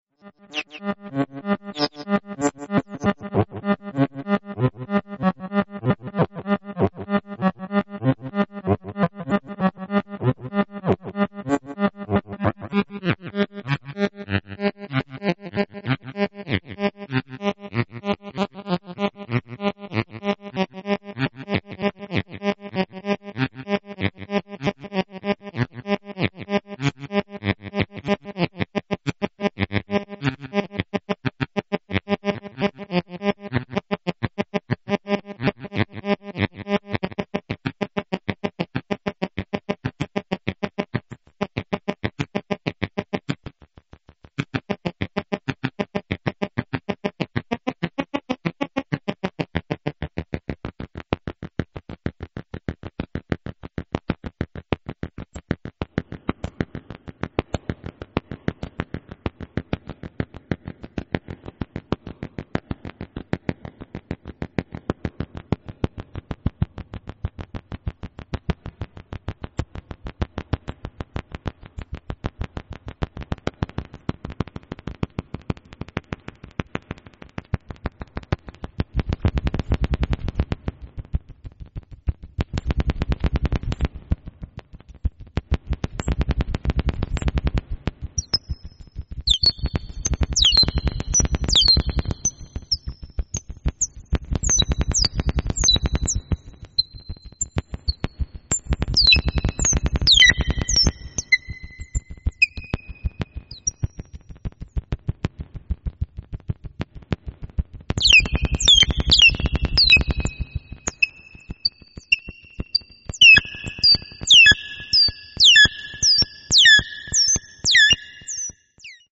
Creator's Tags: drone electronic
Description: A drone with high pitched tweeting made by the 0-Coast